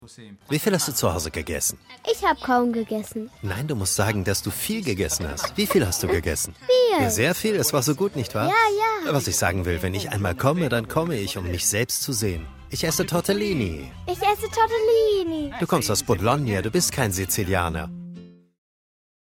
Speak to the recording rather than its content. All our voice over artists are equipped with pro home studios, ensuring top-notch audio quality for your projects.